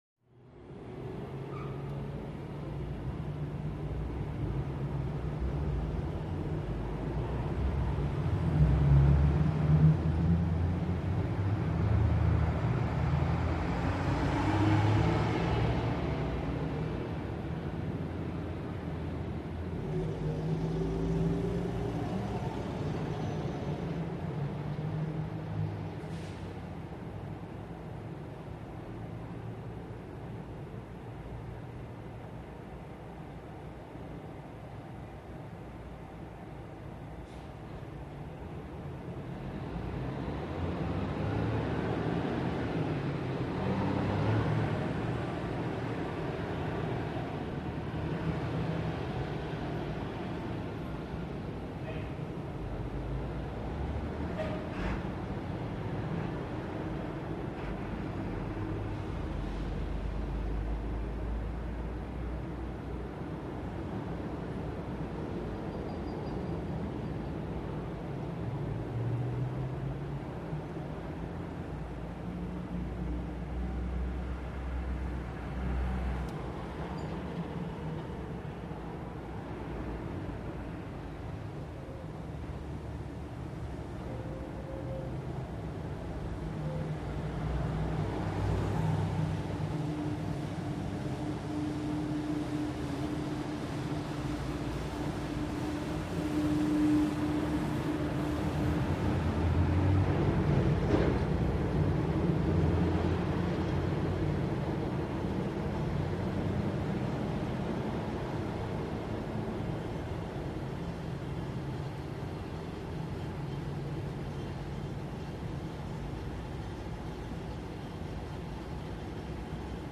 LightTrafficInteri CT051701
Light Traffic, Interior Point Of View, Closed Window, Street side Building. Muffled Cars By Trucks By, Street Cleaner By At 1; 28.